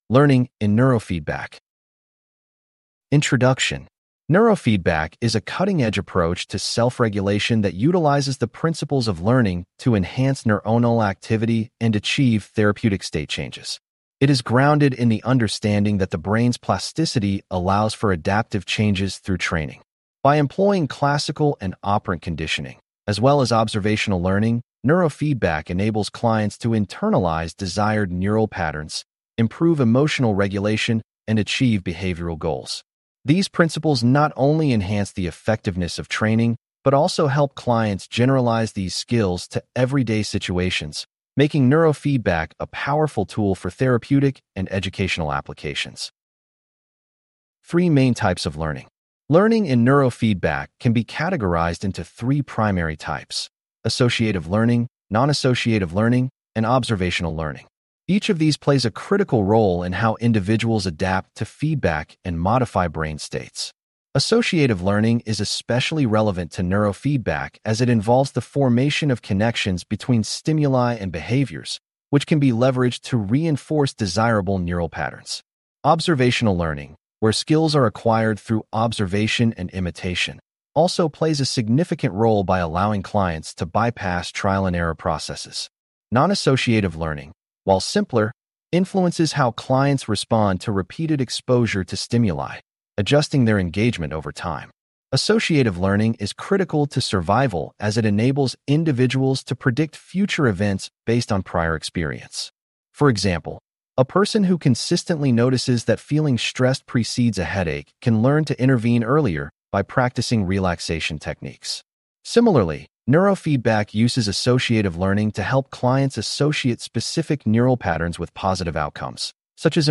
This unit covers: Three Main Types of Learning, Classical Conditioning, Operant Conditioning, Nonassociative Learning, Observational Learning, Critical Elements in Neurofeedback Training. Please click on the podcast icon below to hear a full-length lecture.